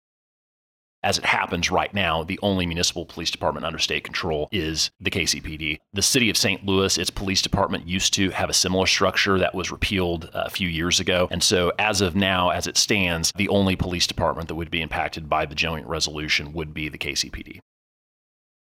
3. Senator Luetkemeyer also says only Kansas City voters would see this on their ballots.